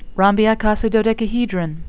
(rom-bi-ico-si-do-dec-a-he-dron)